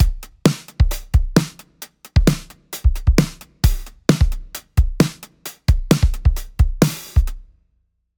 キックの1/8＆1/16ずらし
✨ キックとスネアの打点が近づくことで生じる躍動感だったり、意外なところに打点が来るドッキリ感などが立ち現れてきています。
r1-synco-drum-kick16th.mp3